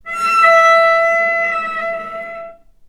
vc_sp-E5-ff.AIF